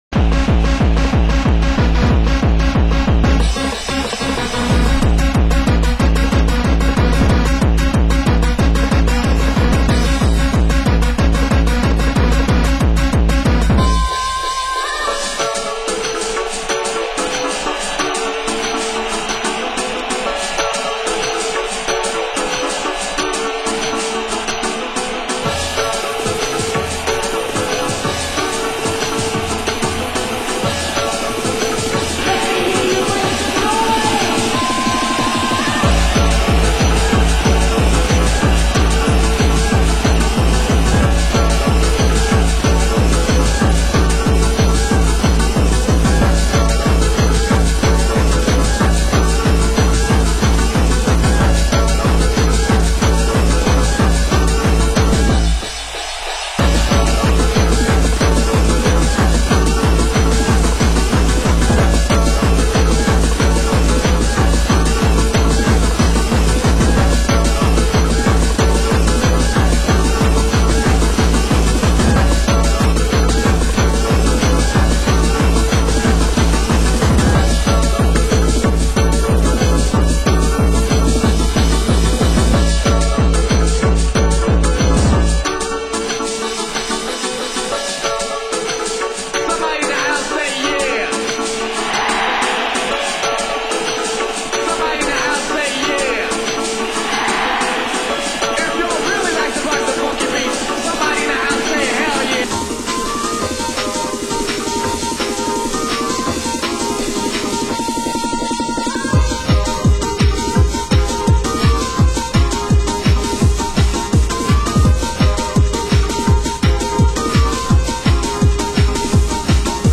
Genre: Happy Hardcore